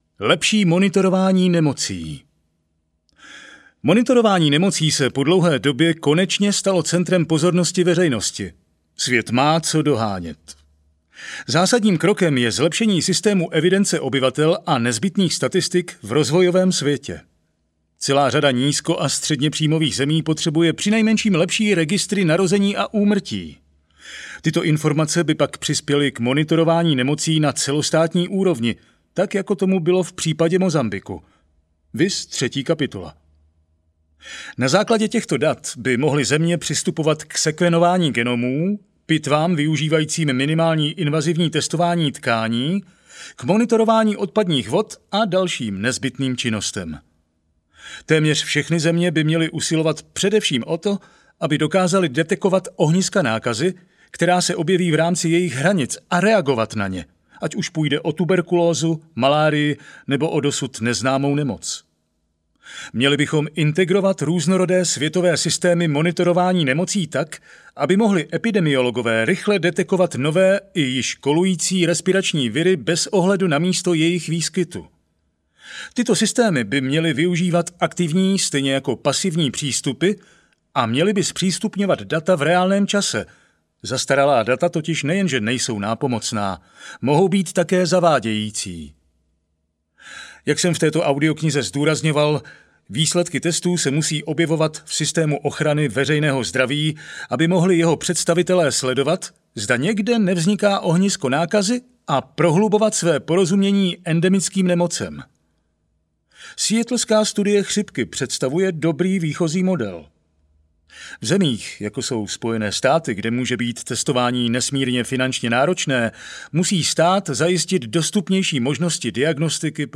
Jak zabránit další pandemii audiokniha
Ukázka z knihy